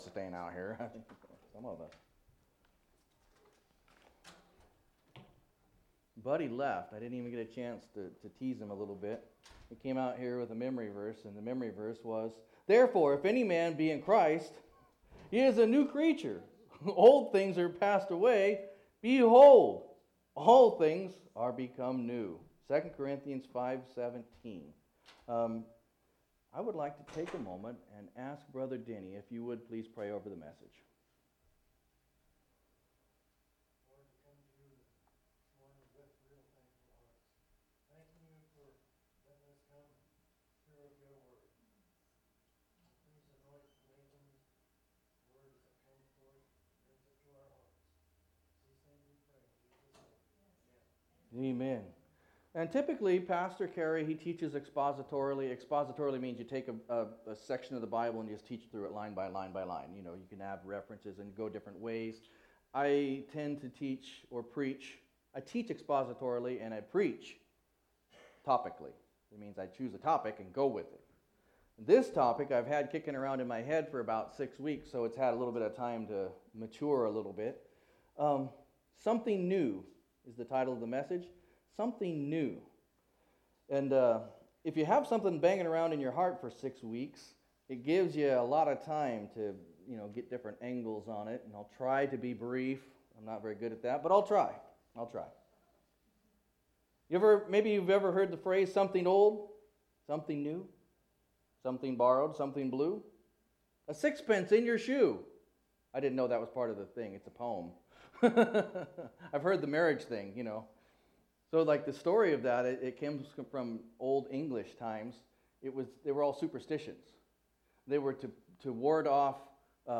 Sunday Morning Service in 2 Corinthians 11:16-30